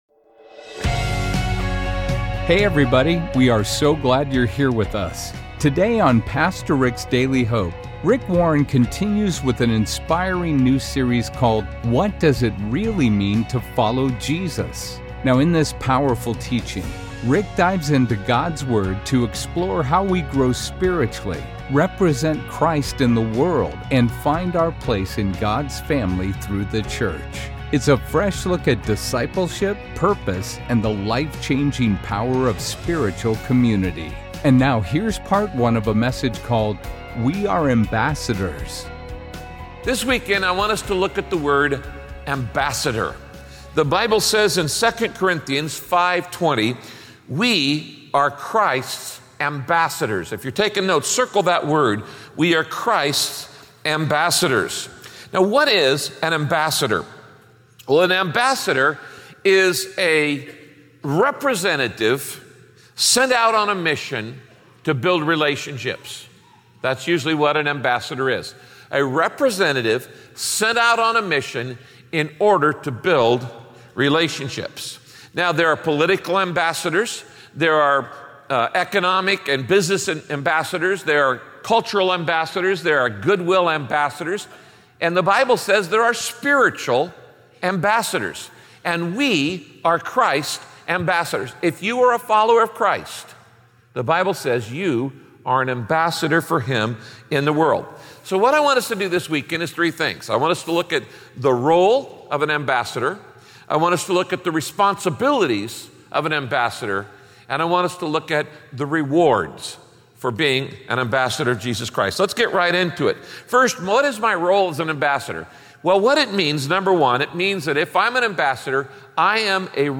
Being an ambassador of Jesus means God has given you a mission and the authority to speak his truth wherever you go. In this message, Pastor Rick dives deep into what it means to be an ambassador for Christ.